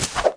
Pickup Wings Sound Effect
pickup-wings-1.mp3